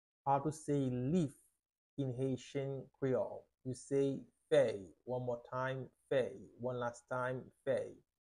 How to say “Leaf” in Haitian Creole – “Fèy” pronunciation by a native Haitian Teacher
“Fèy” Pronunciation in Haitian Creole by a native Haitian can be heard in the audio here or in the video below: